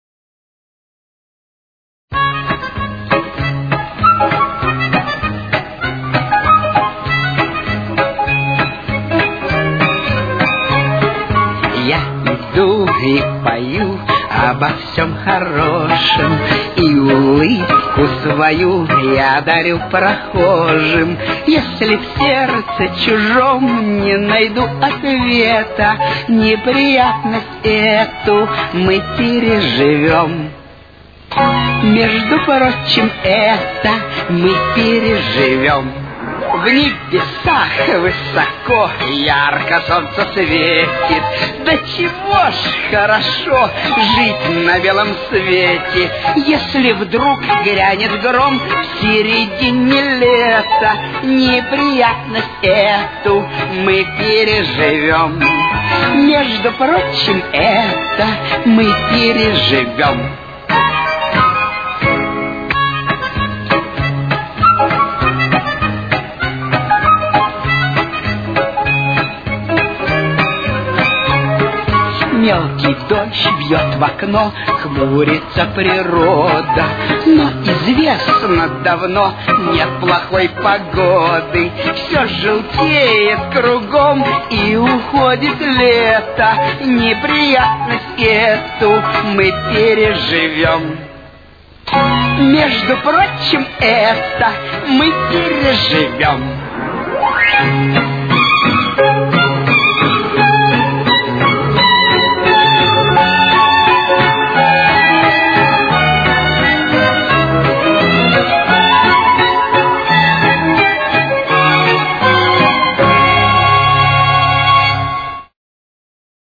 Темп: 101.